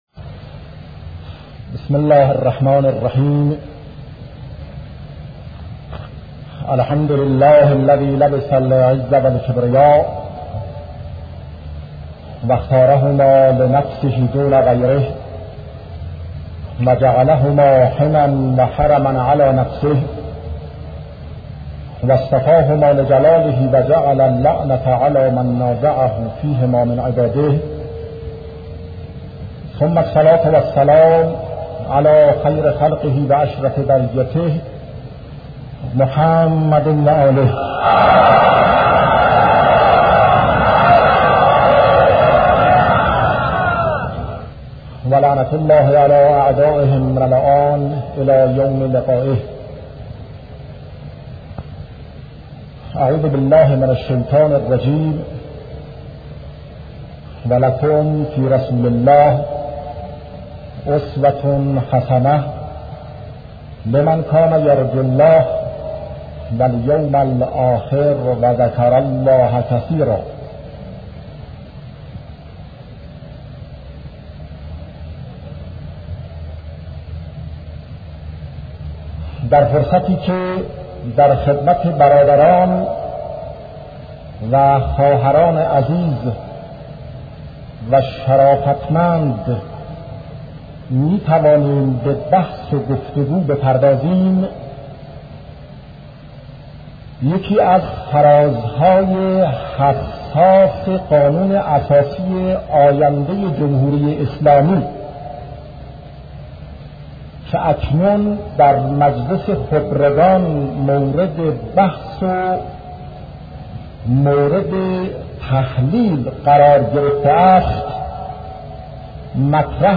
صوت سخنرانی شهید باهنر- با موضوعیت استعمار و استثمار